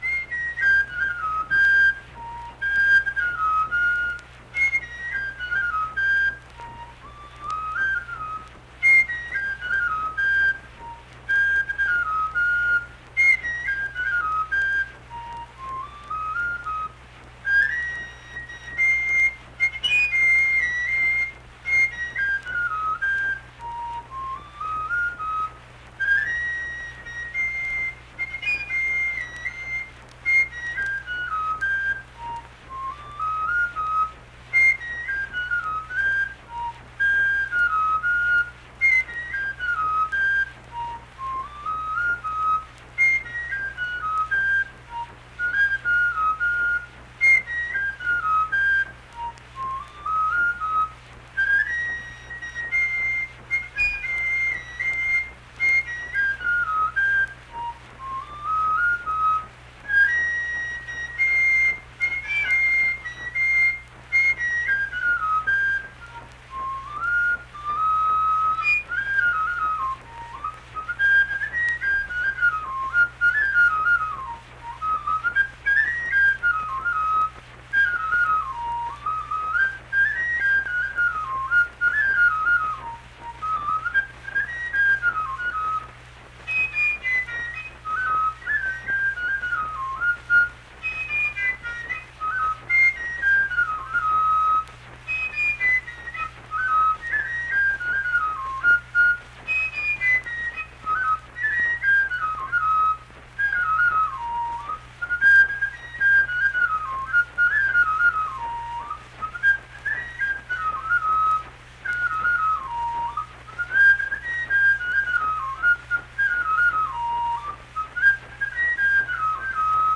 Unaccompanied fife music
Verse-refrain 2(2). Performed in Hundred, Wetzel County, WV.
Instrumental Music
Fife